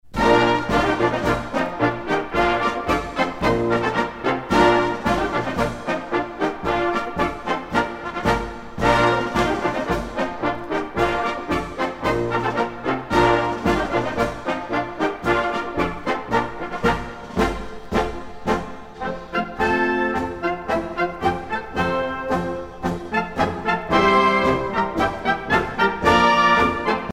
Fonction d'après l'analyste gestuel : à marcher
Catégorie Pièce musicale éditée